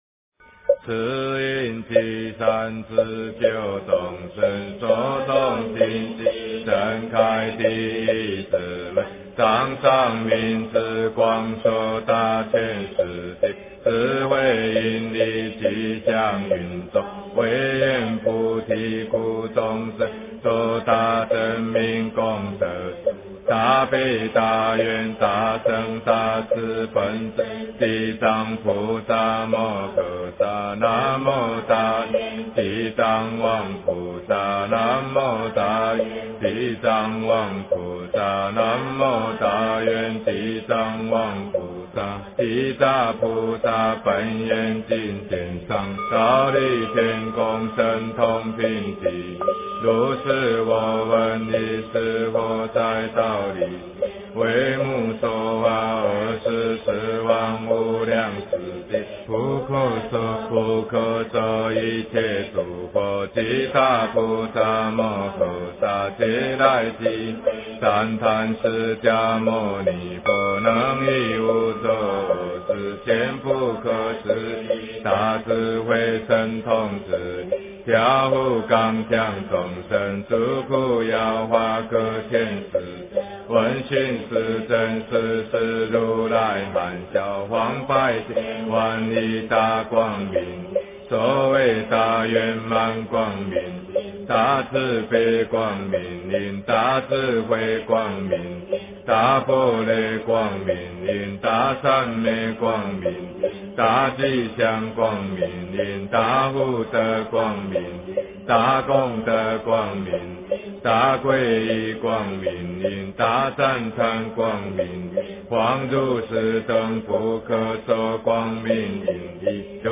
地藏菩萨本愿经（木鱼版）
诵经